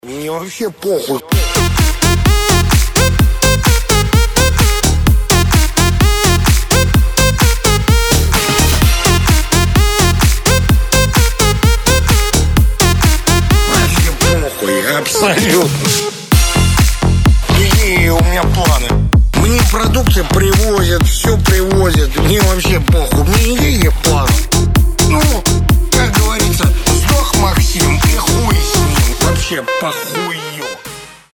• Качество: 320, Stereo
громкие
веселые
EDM
энергичные
electro house
подвижные
Melbourne Bounce